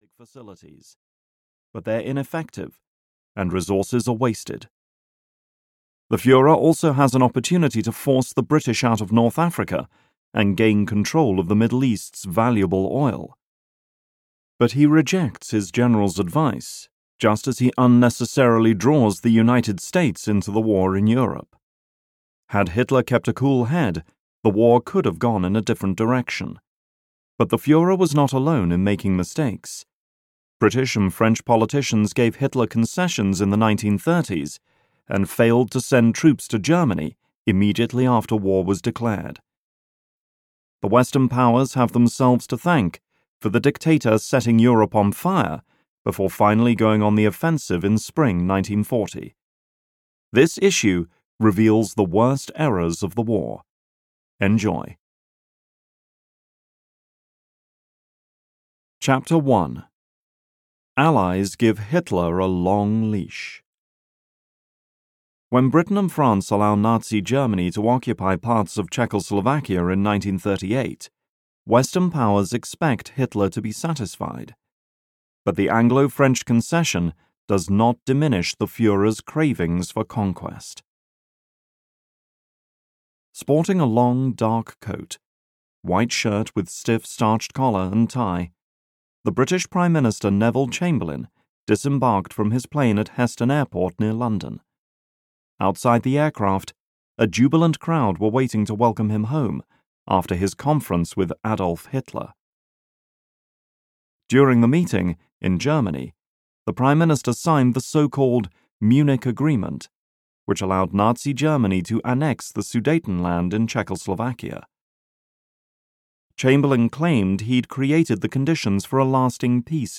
Audio knihaBiggest Blunders of WWII (EN)
Ukázka z knihy